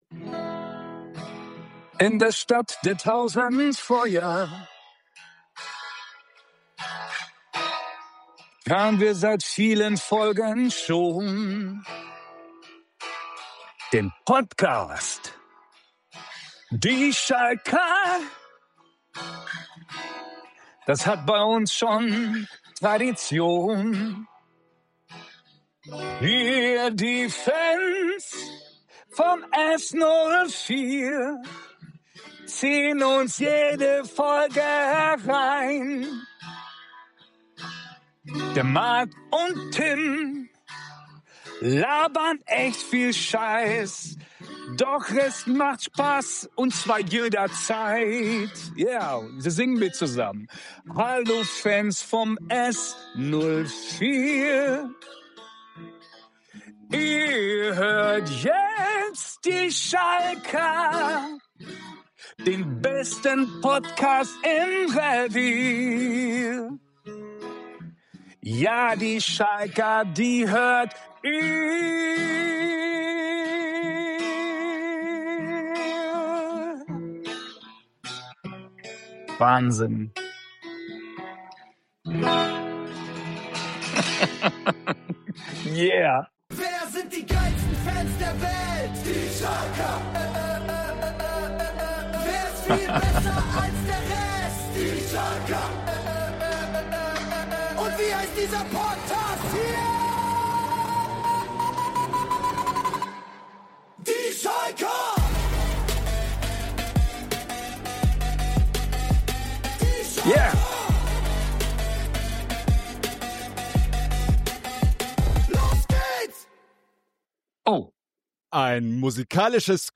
Beschreibung vor 2 Wochen In dieser Episode haben wir zwei Männer zu Gast, zu deren Liedern wir geweint, gefeiert, gesungen und getanzt haben.